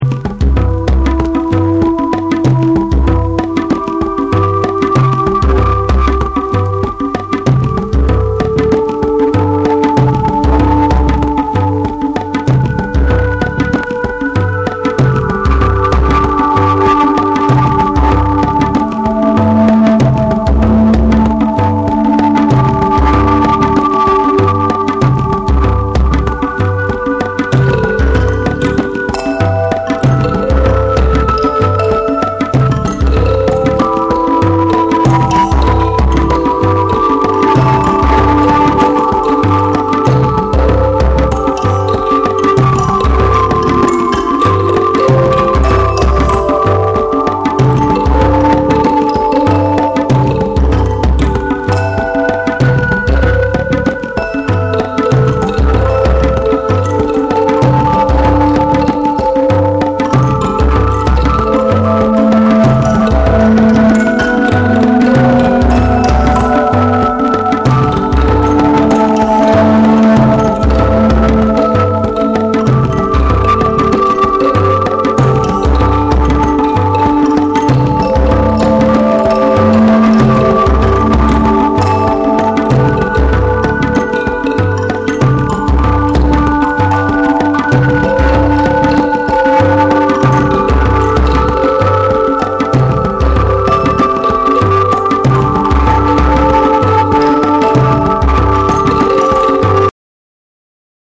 オーガニックで浮遊感に満ちたサウンドスケープを描く
柔らかなブリージン・グルーヴが広がる
タブラのリズムを取り入れたオーガニックなトラックにシタールのフレーズが溶け込むオリエンタル・ナンバー
JAPANESE / BREAKBEATS / ORGANIC GROOVE